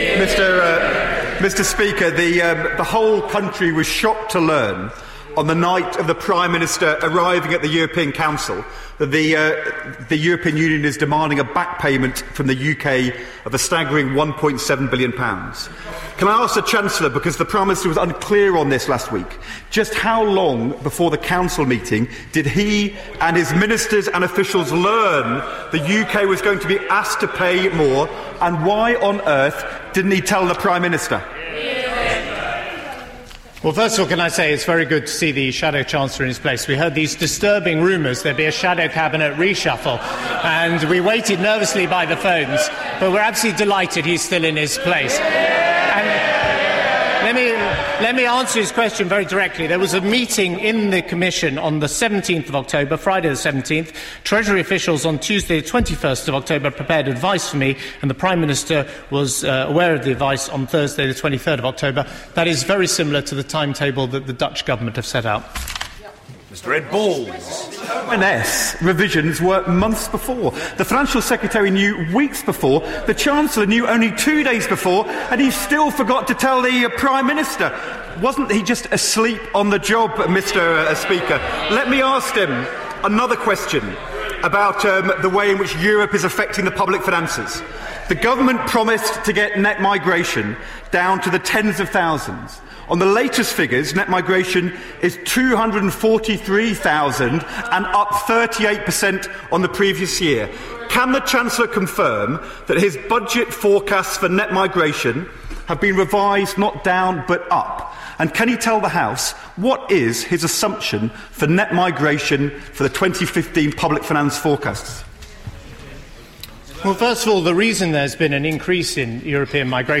House of Commons, 4 November 2014